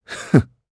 Lusikiel-Vox_Happy1_jp.wav